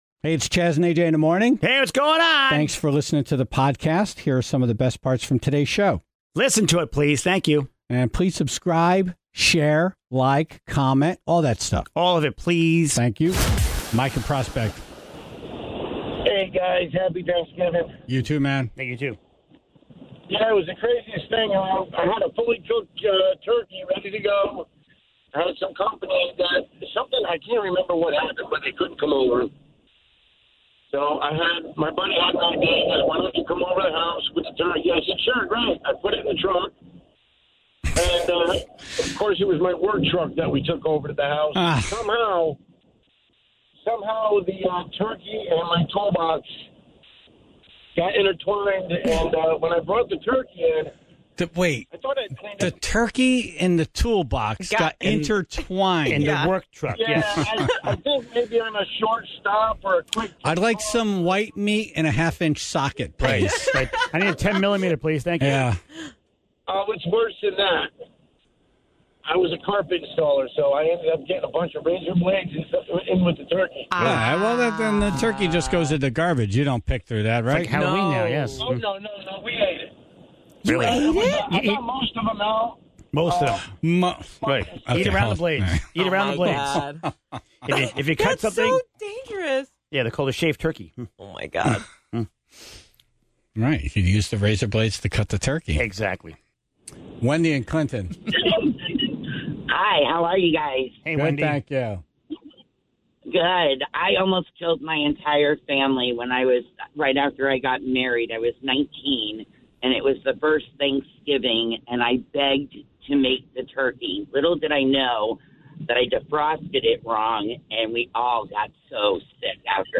The Tribe calls in cooking disasters, including the time one Tribe member poisoned her entire family with a diseased turkey (0:00)